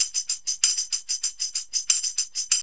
TAMB LP 96.wav